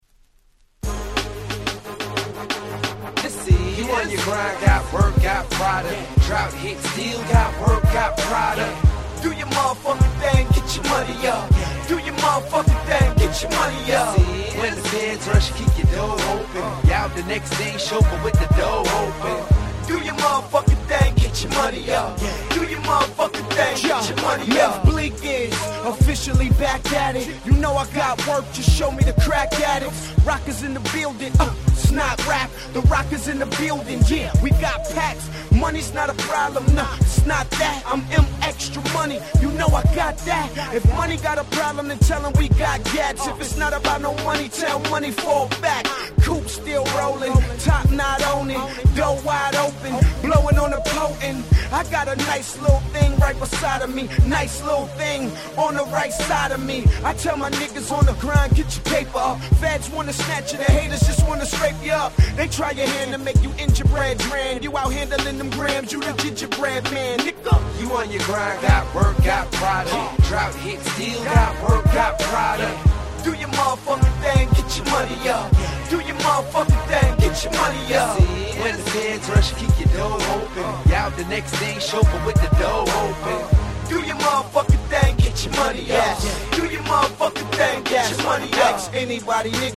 08' Nice Hip Hop !!